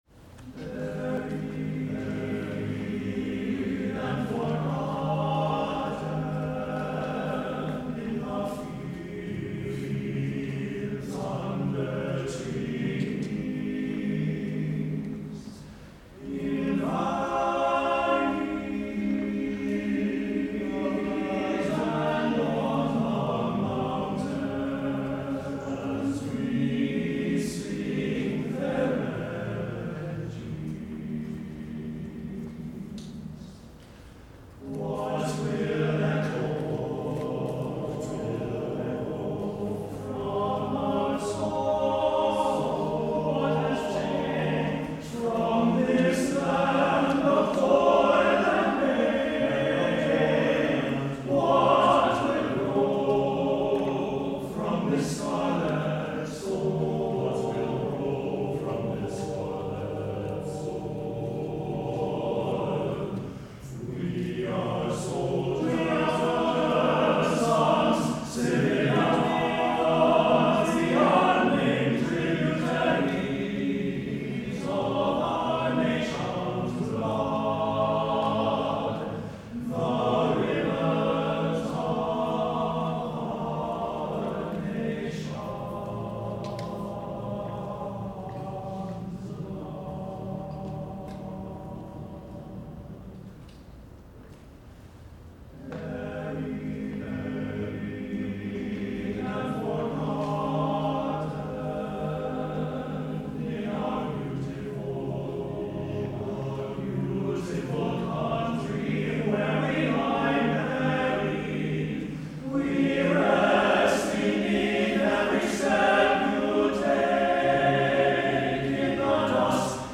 CHORAL:
Instrumentation: TTBB